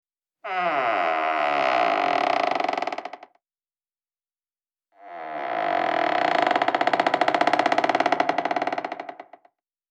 porta a abrir e a fechar.
porta_abrir_fechar.wav